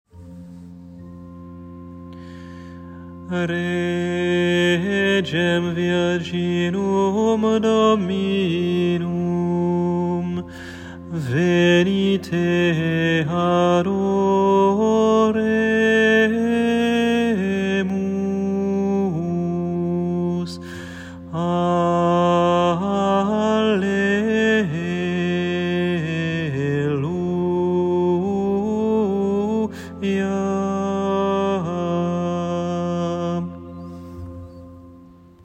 Antienne invitatoire (temps pascal) : Regem virginum [partition LT]